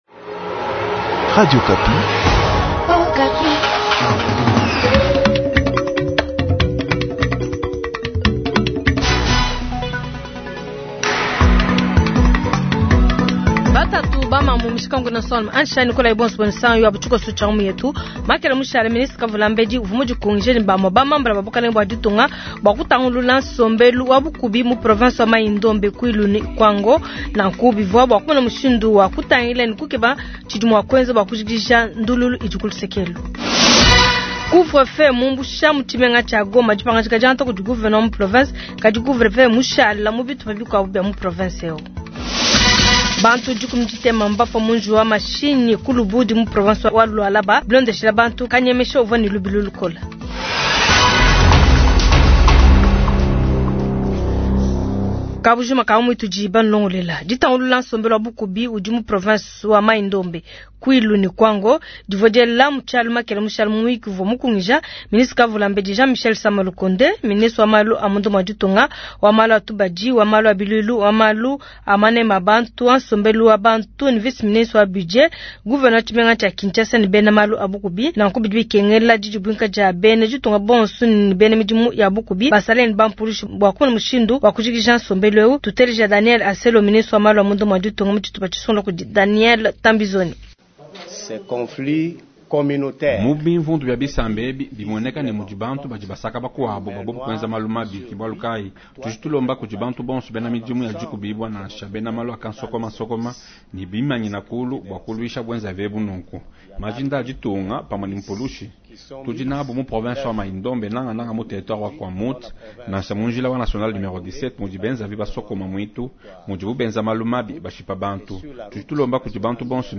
Journal soir
Goma : vox pop sur la levée de couvre- feu dans la ville